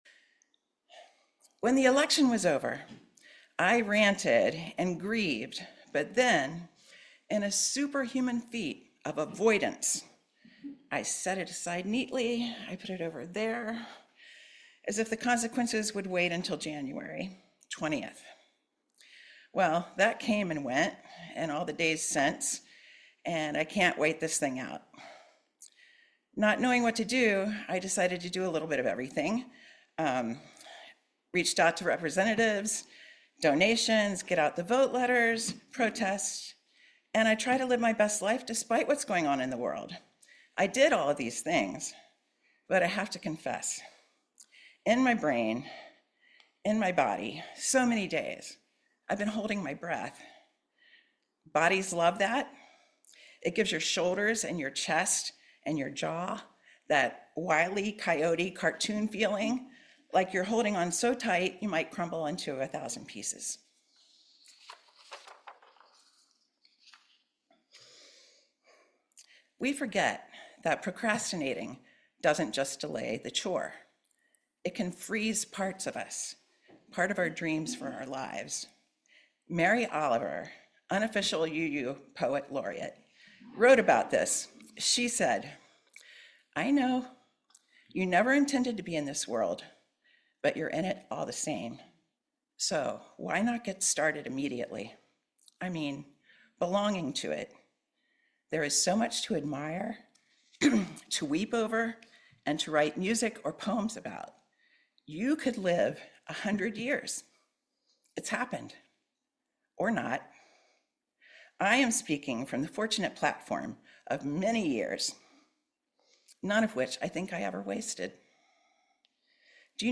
In this sermon, the speaker explores how to navigate political and personal turmoil without succumbing to emotional paralysis.